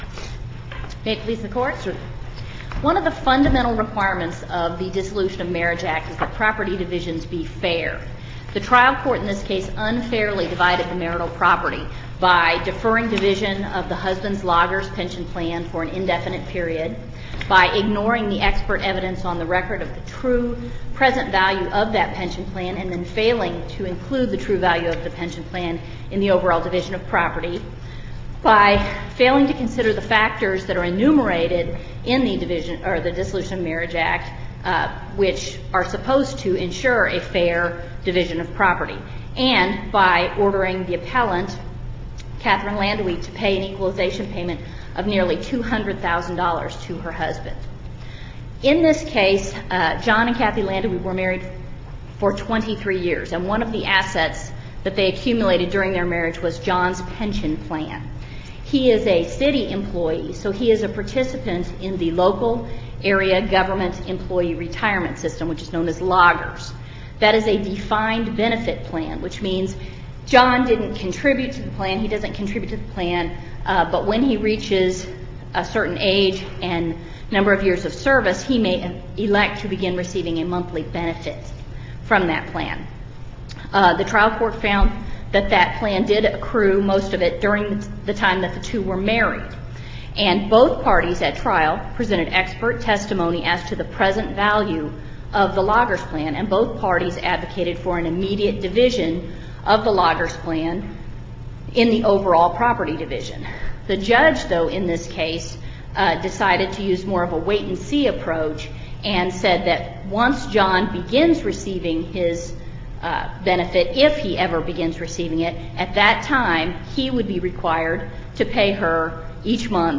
MP3 audio file of oral arguments in SC95918